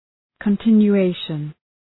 Προφορά
{kən,tınju:’eıʃən}